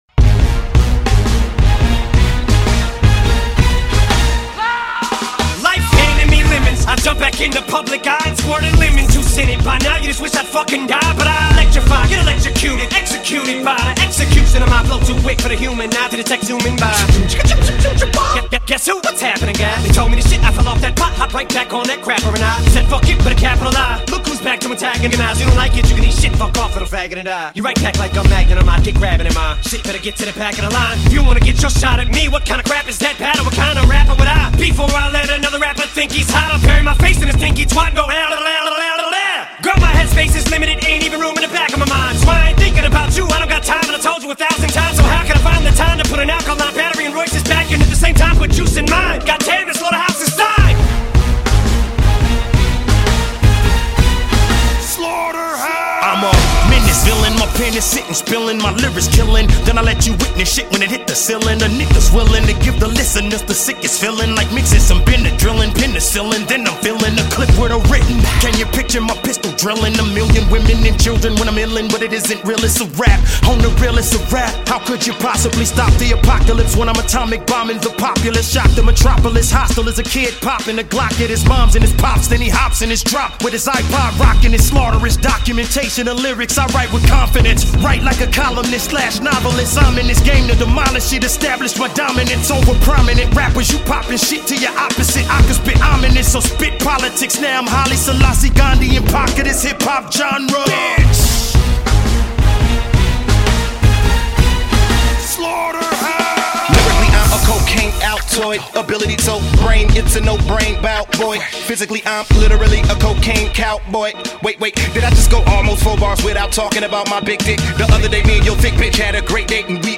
rap музыка